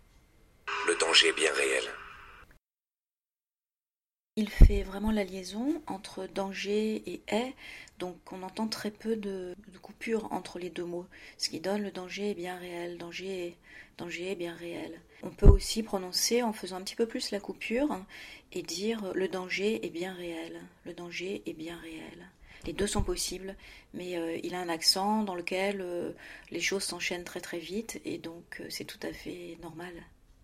C’est vrai que lorsque nous parlons, les deux sons (-er et est) s’enchaînent en général sans pause entre les deux mots.
Ecoutez la différence (infime) lorsqu’il y a une toute petite coupure entre les mots ou pas:
selfie-prononciation-1.mp3